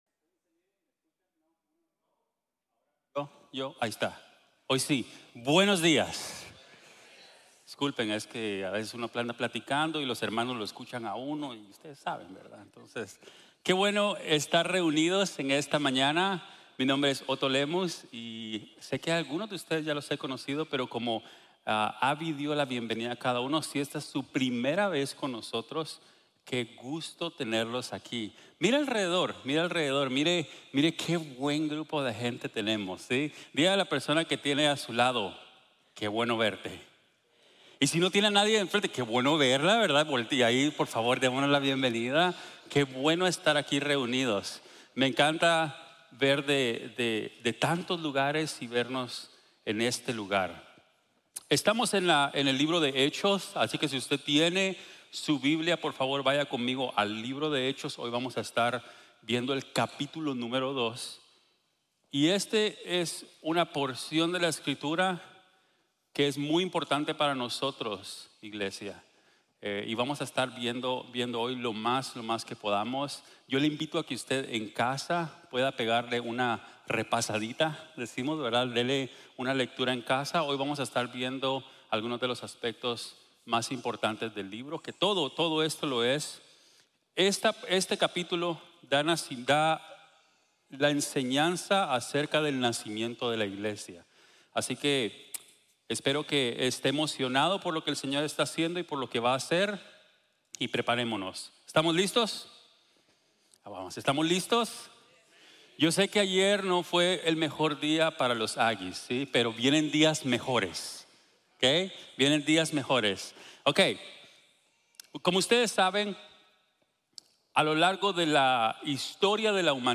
El Espiritu viene: nace la iglesia | Sermon | Grace Bible Church